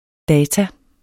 Udtale [ ˈdæːta ]